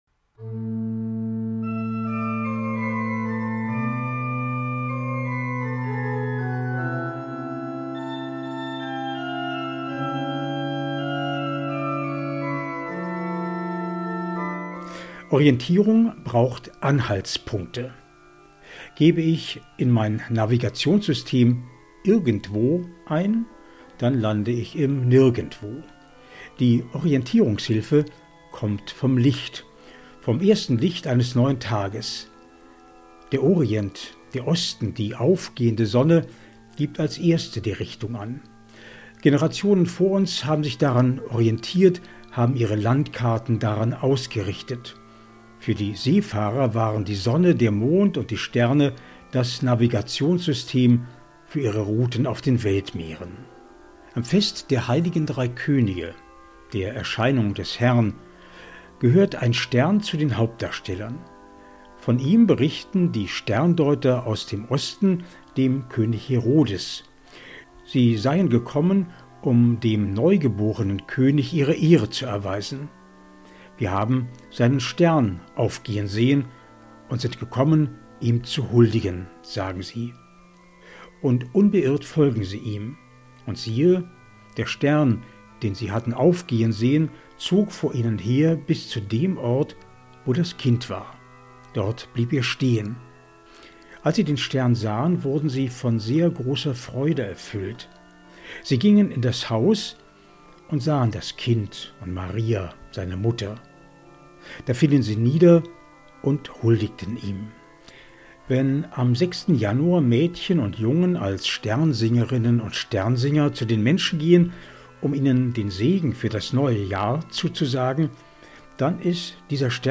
MEDITATION
Musik: privat